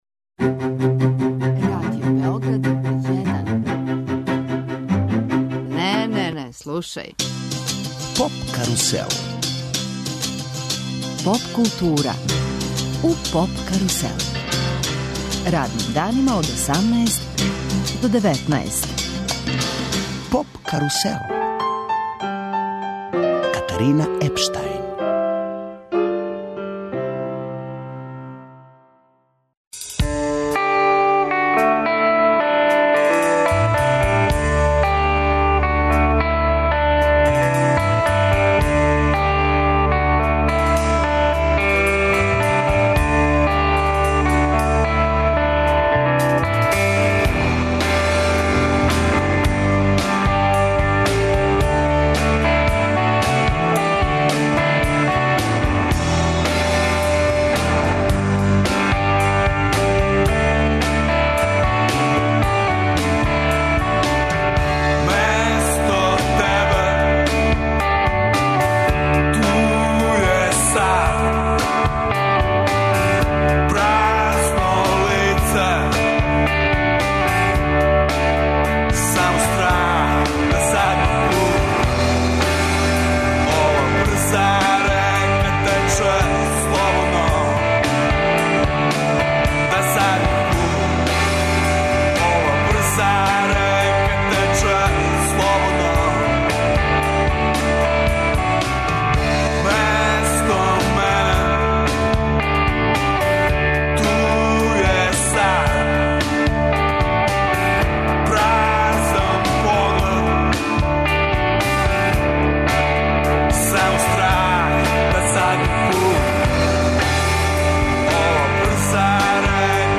Емитујемо интервју са чувеним диригентом Зубином Мехтом. Маестро ће осми пут дириговати Београдском филхармонијом на Гала концерту, који ће се одржати 20. септембра у Задужбини Илије Коларца.